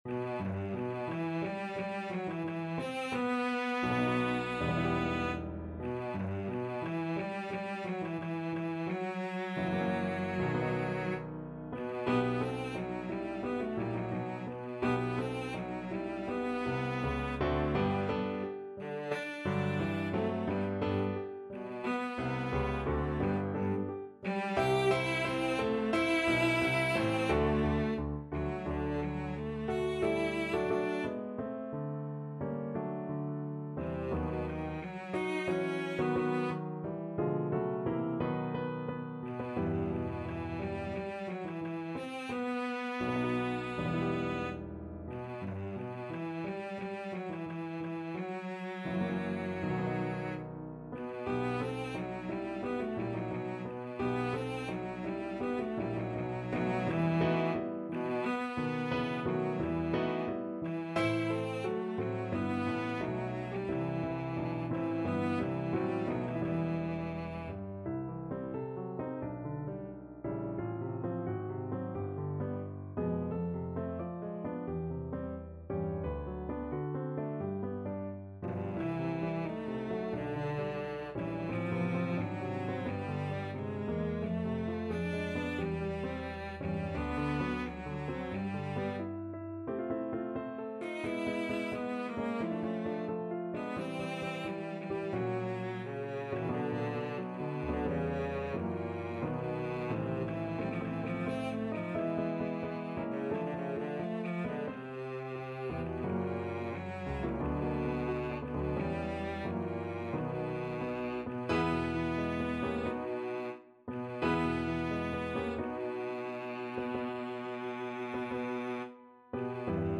=88 Nicht schnell =100
Classical (View more Classical Cello Music)